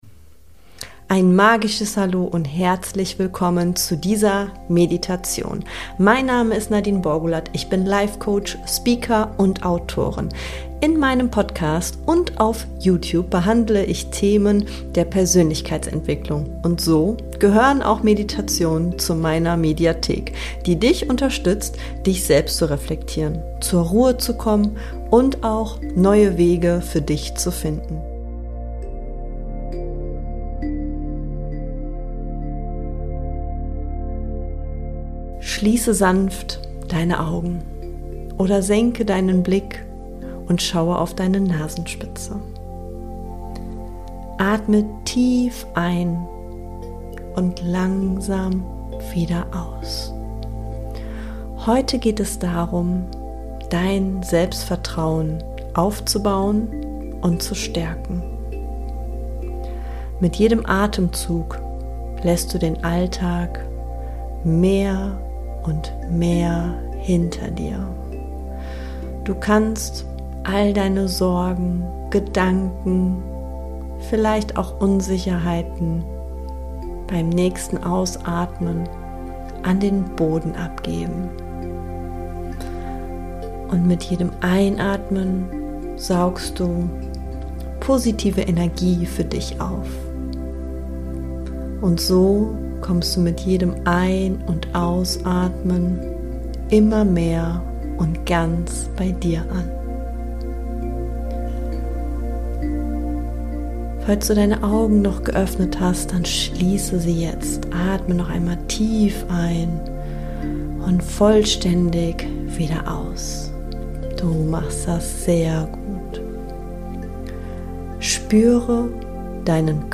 Erlebe eine geführte Meditation für mehr Selbstvertrauen, Selbstliebe und innere Stärke – begleitet von inspirierenden Bali-Videos. Finde Klarheit, löse Selbstzweifel, stärke deine Achtsamkeit und tanke neue Energie für dein persönliches Wachstum.